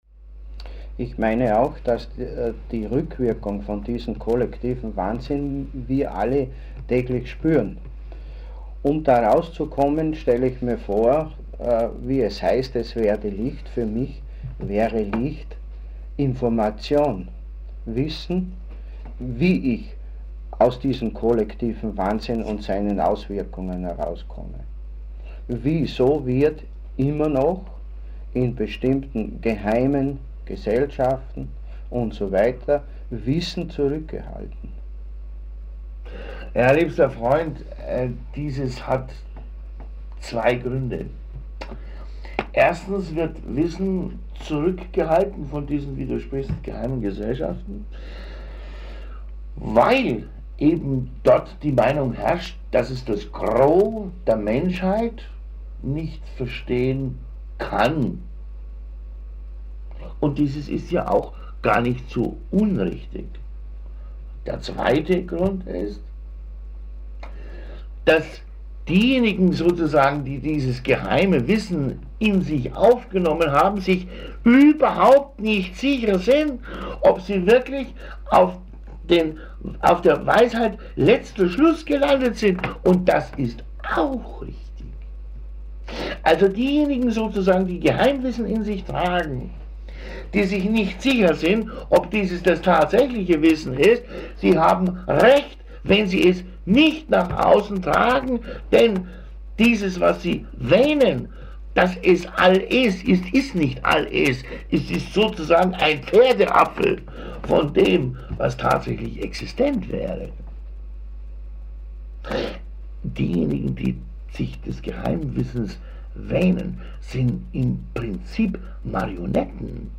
Alle Aufnahmen entstanden in Vorarlberg/Österreich.
Diese Serie von Readings entstanden in den Jahren 1996 bis 1999 und wurden auf Audio-Kassetten mitgeschnitten.
Leider sind viele dieser CDs im laufe der Jahre von der Tonqualität her sehr in Mitleidenschaft gezogen, d.h. unbrauchbar, worden.